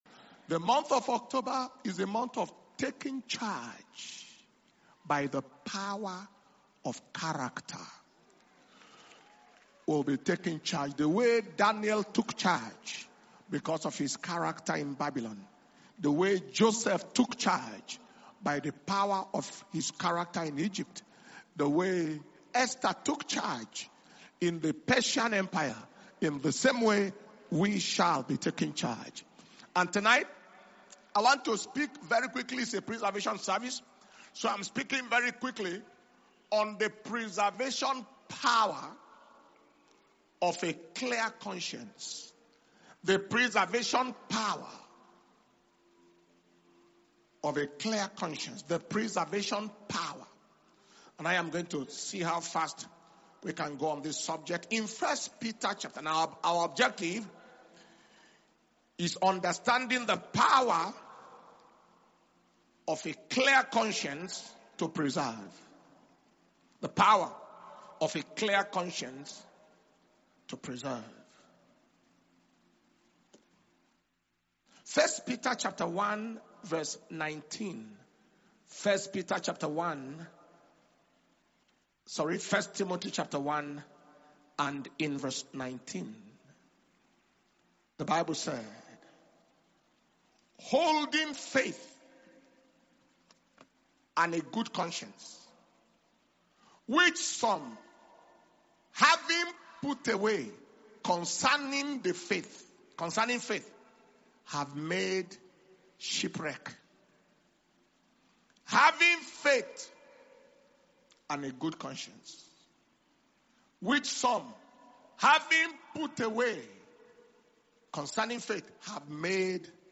October 2025 Preservation and Power Communion Service